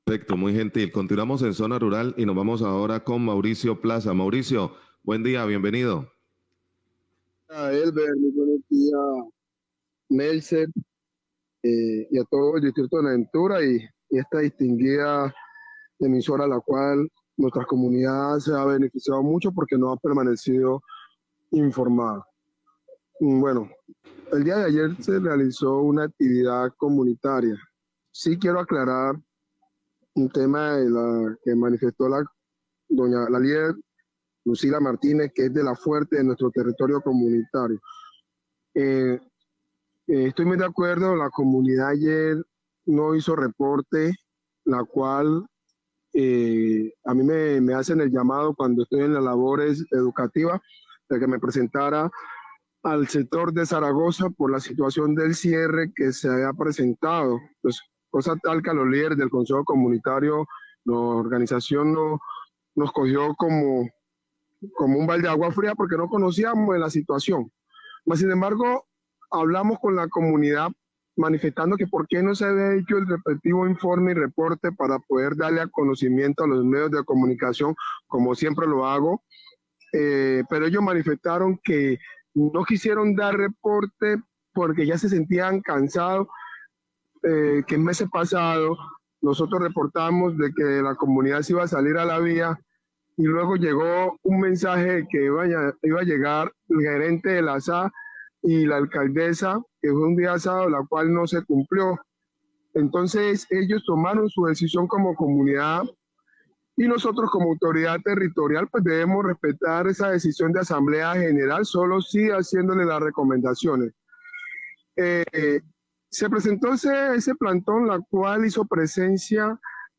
Oyente hace llamado a Celsia para que asista a reunión en Zaragoza por cortes de energía
Radio